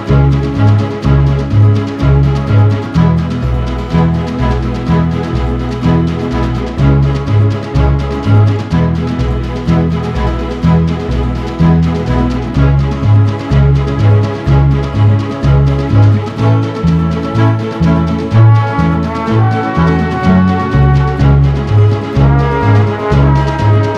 no Backing Vocals Country (Male) 2:57 Buy £1.50